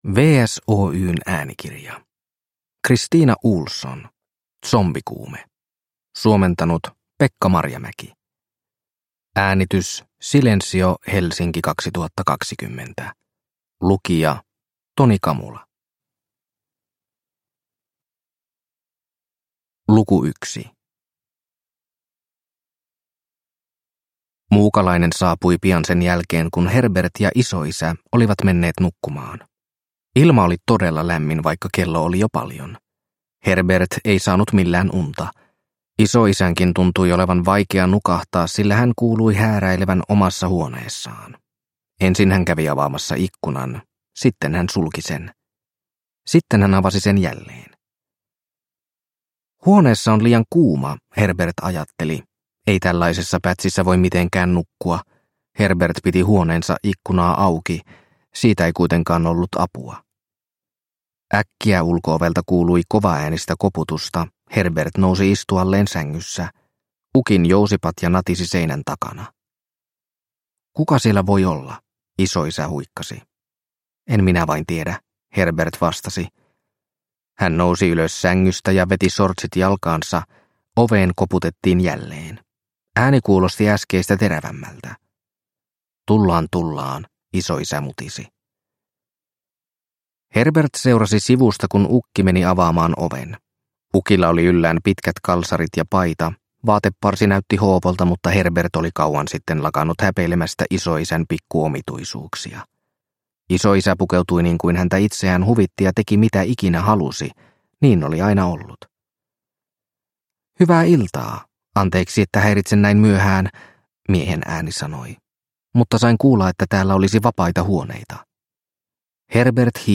Zombikuume – Ljudbok